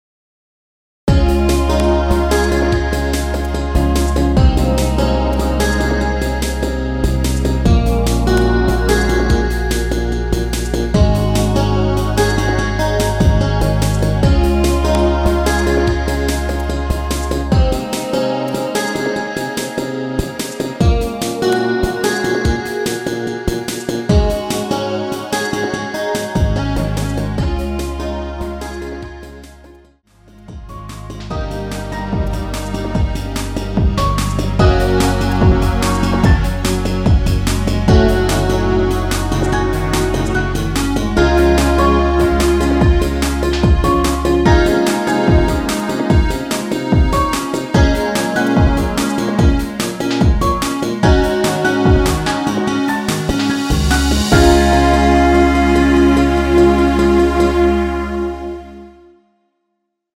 MR입니다.
엔딩이 길고 페이드 아웃이라서 노래 끝나고 8마디 진행후 엔딩을 만들었습니다.
Db
앞부분30초, 뒷부분30초씩 편집해서 올려 드리고 있습니다.
중간에 음이 끈어지고 다시 나오는 이유는